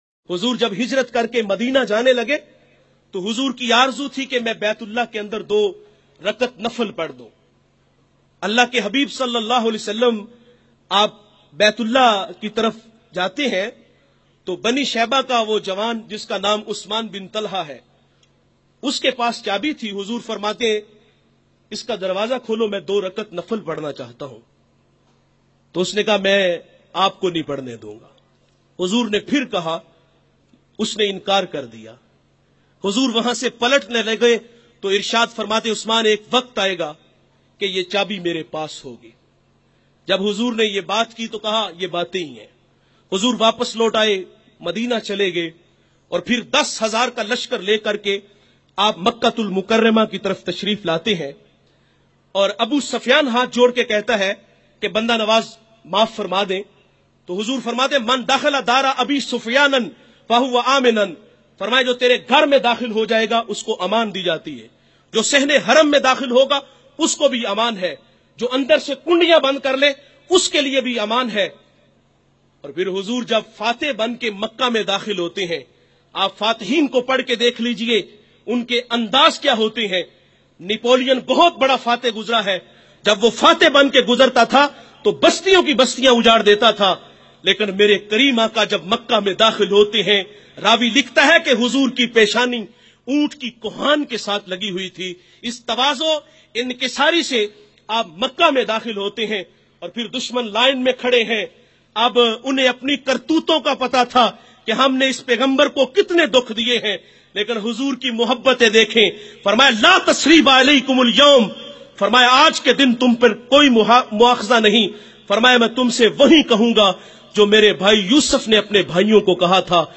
Hazur (SAW) ka Hunsny Saluq Dushmano k Leya bayan mp3